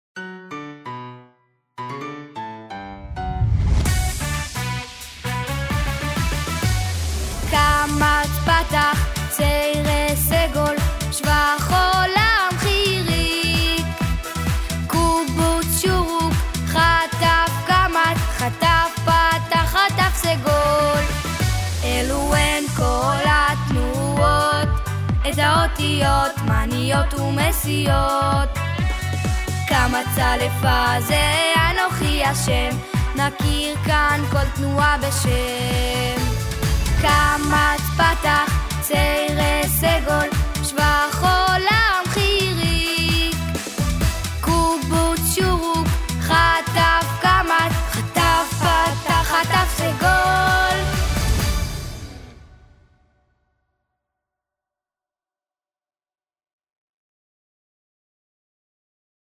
שירים מוקלטים לשינון יומי של שמות האותיות והתנועות תוך מעקב באצבע על לוחות מתאימים.